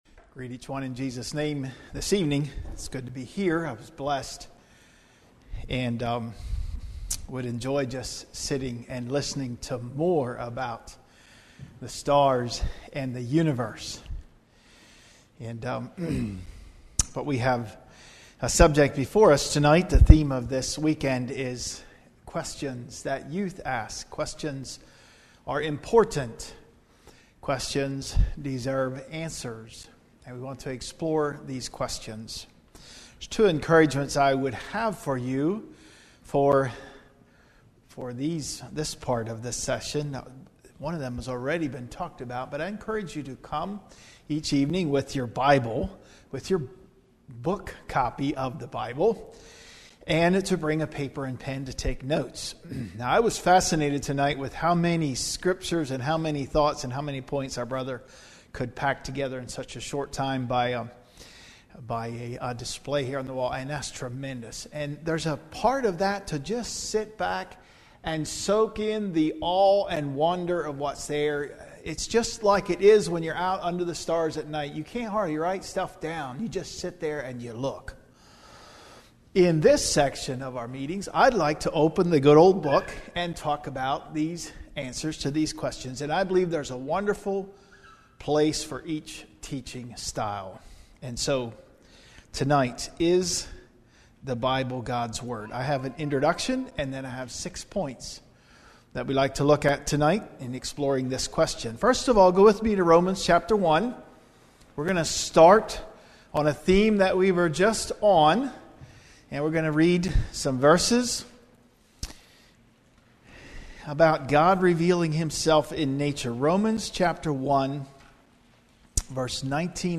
Teaching on the belief that the Bible is the word of God. We must read it and cherish it.